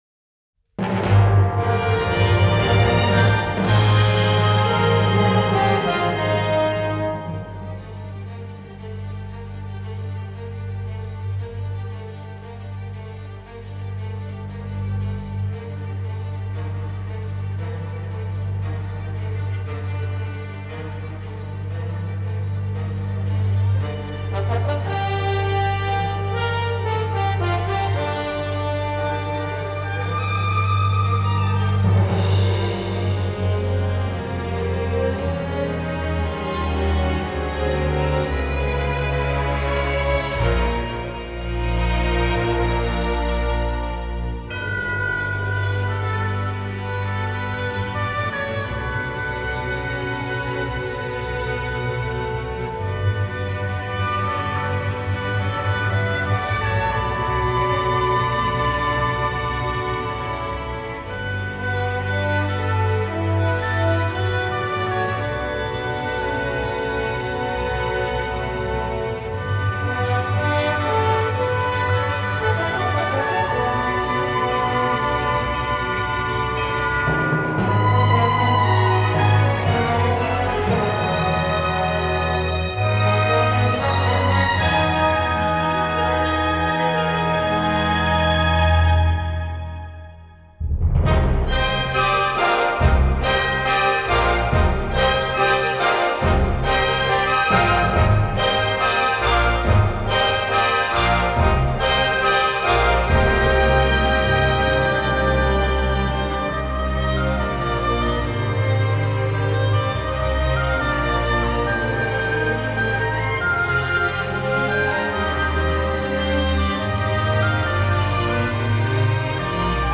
Genre: Indie.